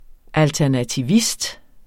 Udtale [ altæɐ̯natiˈvisd ]